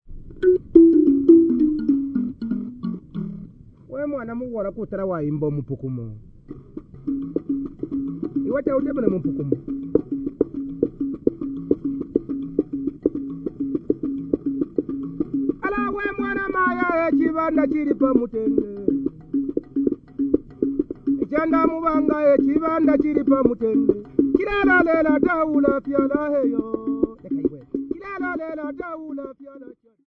Folk music--Africa
Field recordings
Africa Zambia Kitwe f-za
sound recording-musical
Mupukumo drinking song with a Munamba mbira bell, about how his farm failed and then a wagtail bird spoke to him in his language about when he was going to have a child.